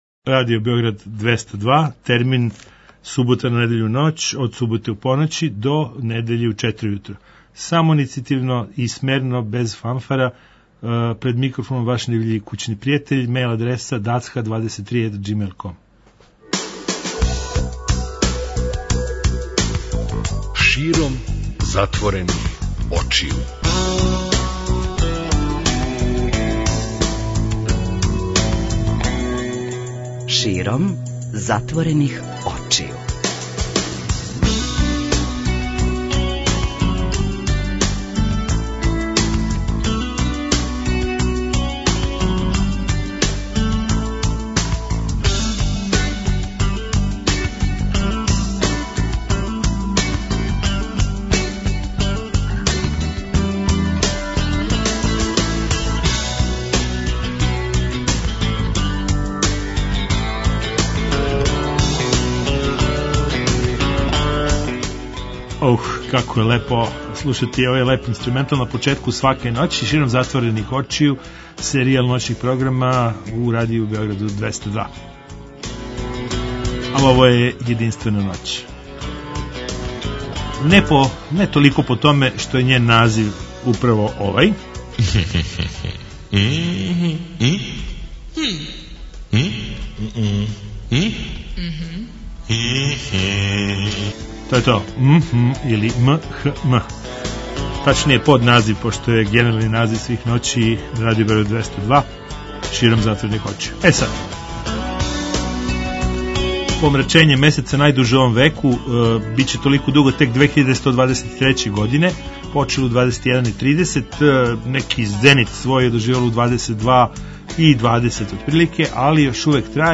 Ноћни програм Београда 202.